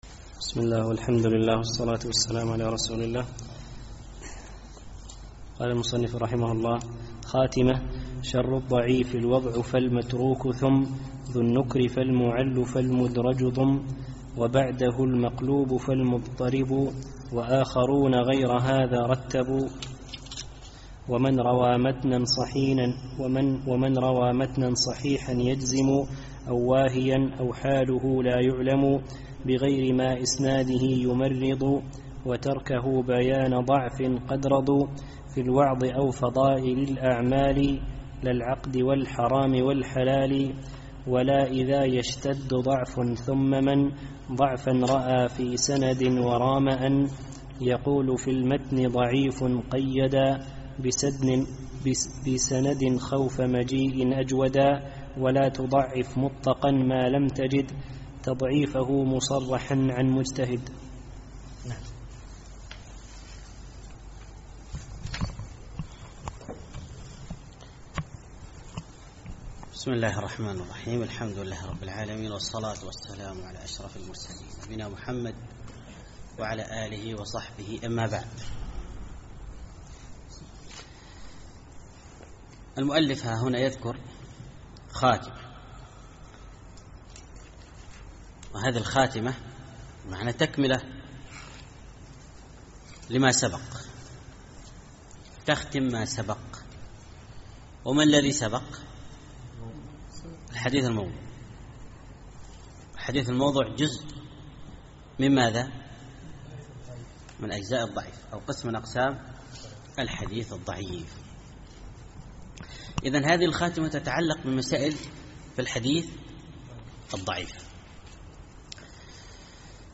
الدرس الرابع عشر